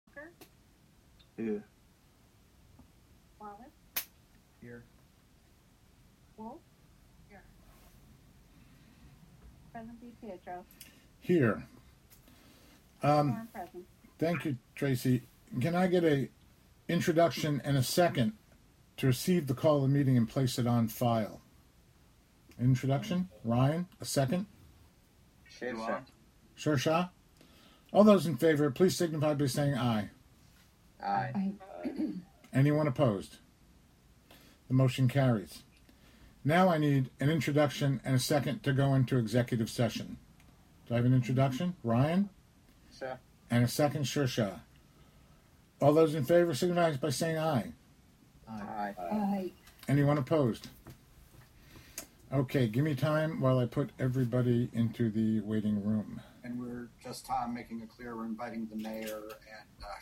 Live from the City of Hudson: Hudson Common Council (Audio)